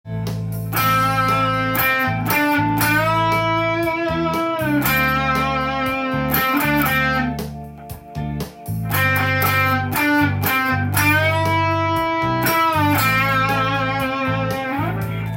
チョーキングを入れてみる
弦を一気に上げて現在弾いている音程を１音上昇させます。
この時もやはり　伸ばしたり　休んでみたりと　リズムに